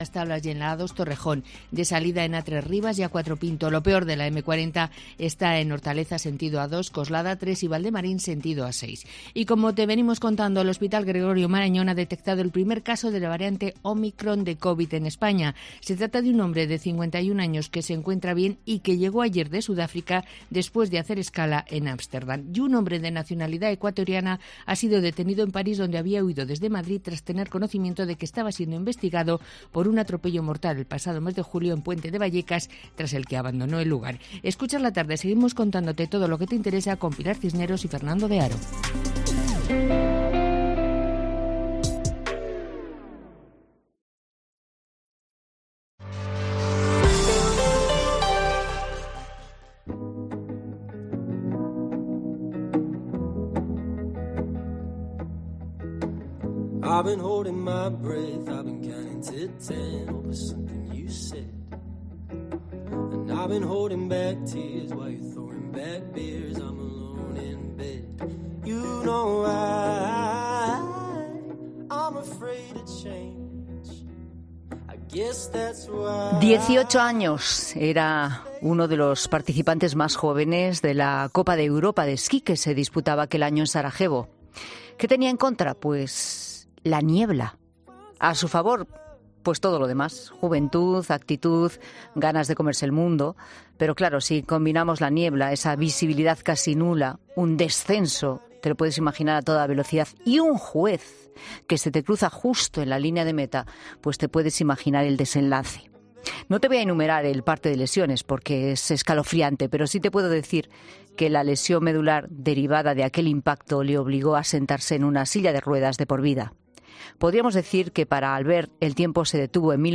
Albert Llovera ha contado su historia en 'La Tarde' de COPE y cómo superó ese trágico incidente que le cambió la vida
Este ejemplo para la población ha sido entrevistado en 'La Tarde' de COPE y ha contado su inspiradora historia.